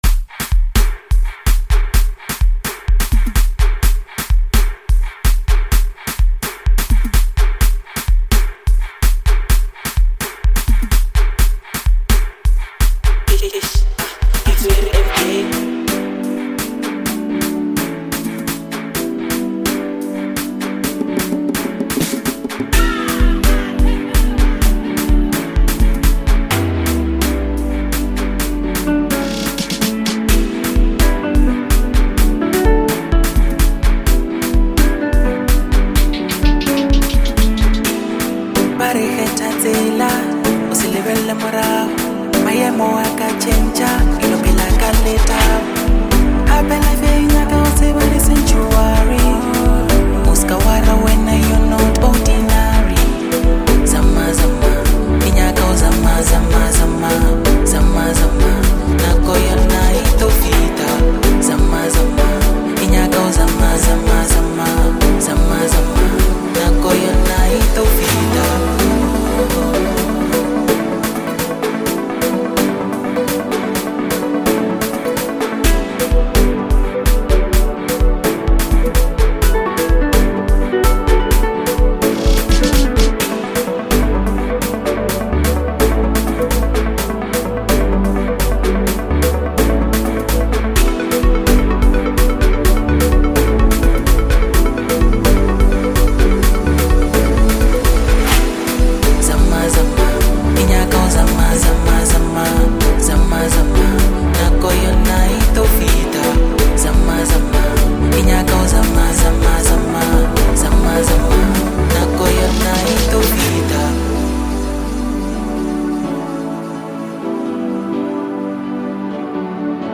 a powerful and motivational anthem